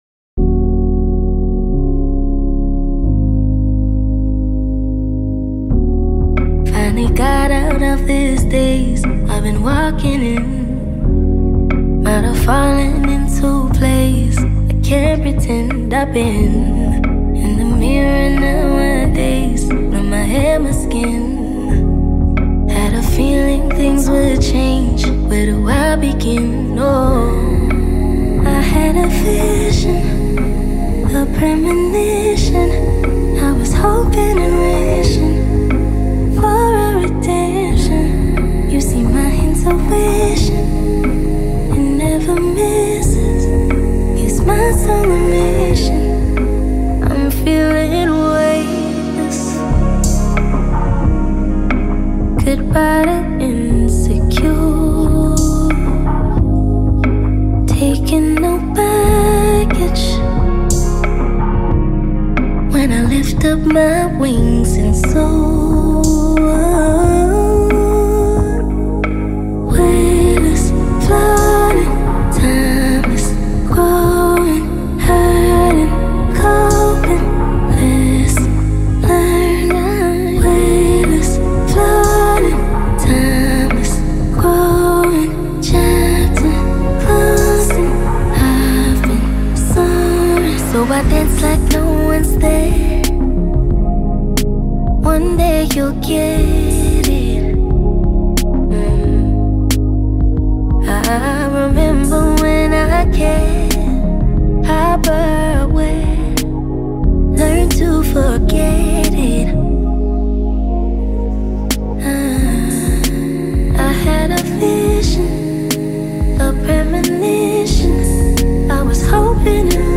Canadian R&B
empowering track